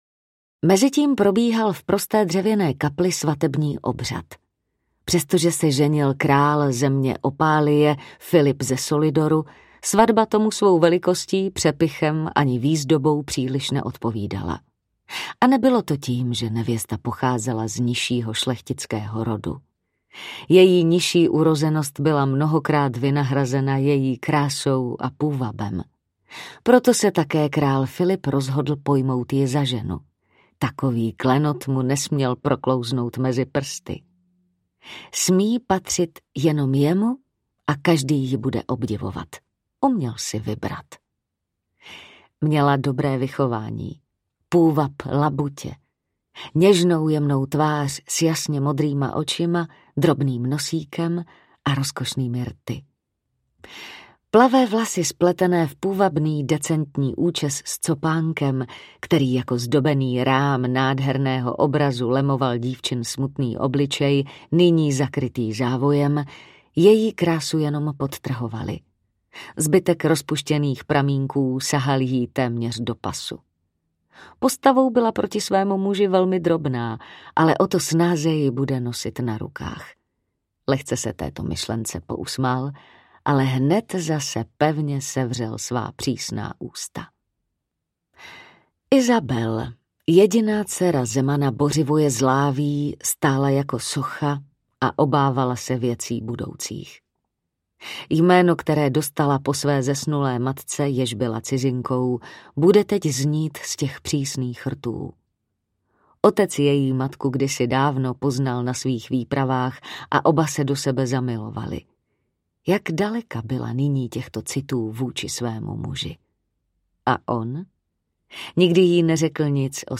Lvice v erbu krále audiokniha
Ukázka z knihy
Vyrobilo studio Soundguru.